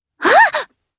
Lavril-Vox_Attack3_jp.wav